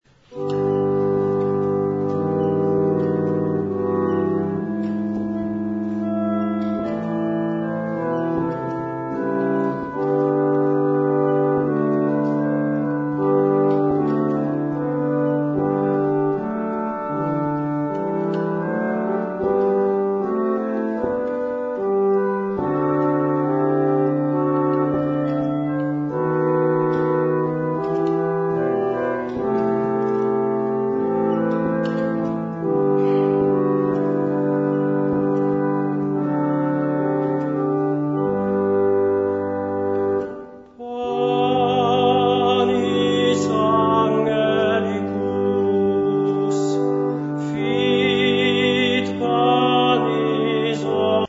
Zde jsou uvedeny ukázky skladeb, které zazněli na koncertě.